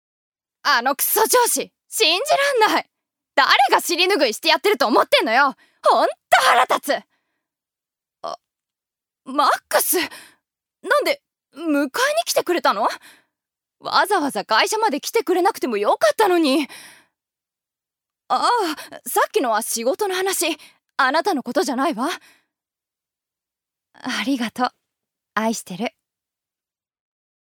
預かり：女性
セリフ３